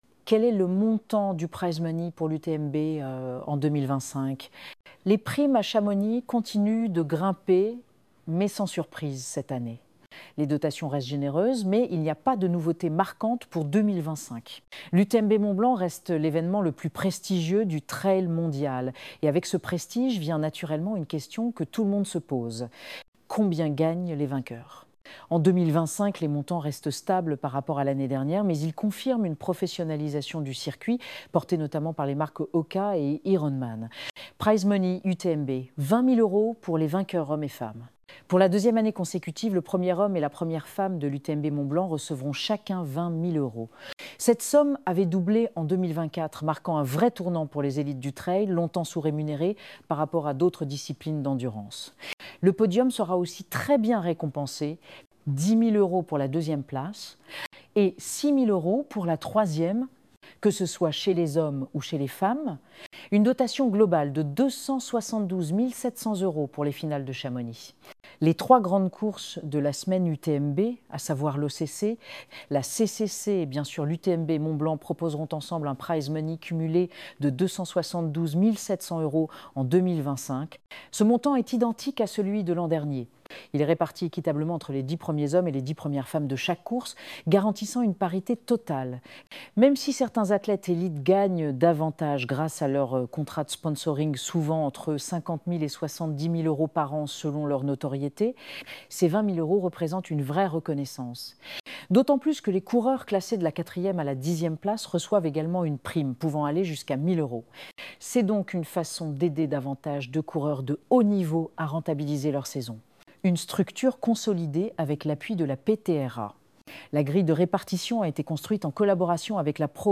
Ecouter cet article : combien gagnent les vainqueurs de l’UTMB